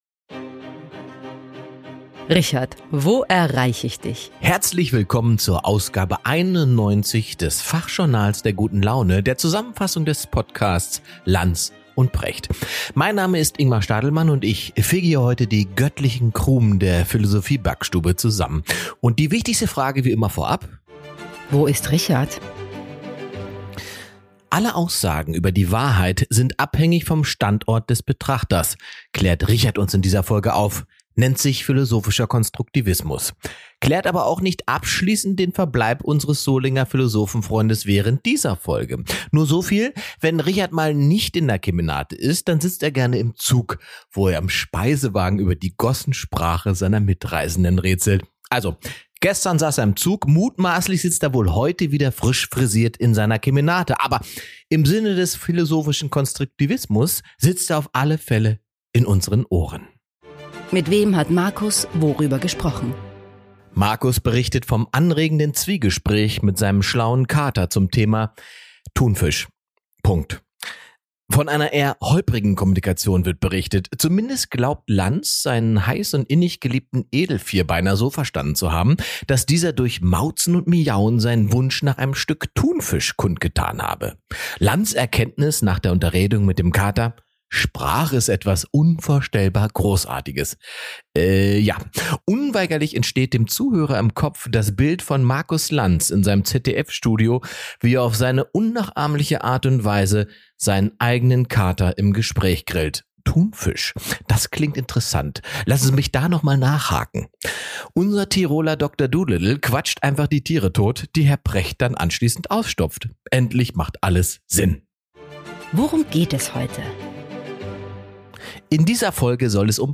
In dieser Podcast-Episode fasst Ingmar Stadelmann die 91. Ausgabe der Sendung "Lanz & Precht" zusammen.